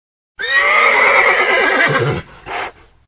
» Конь ржет Размер: 27 кб